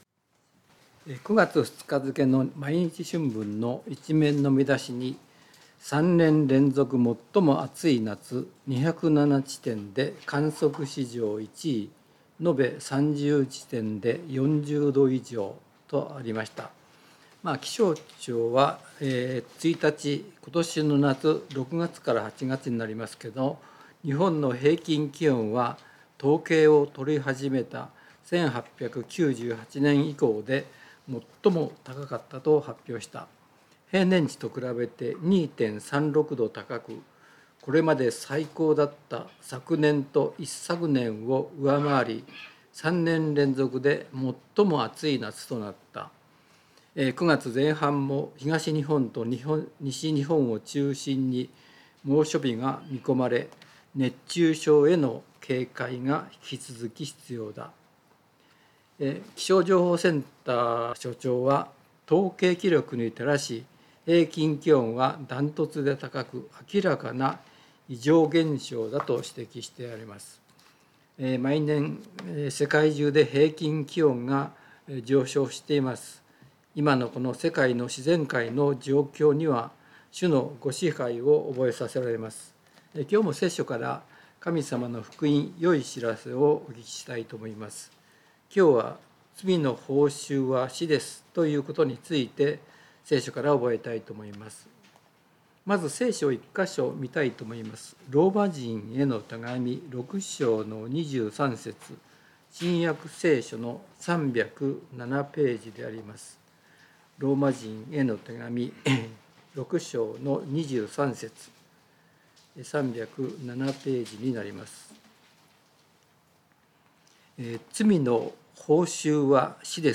聖書メッセージ No.284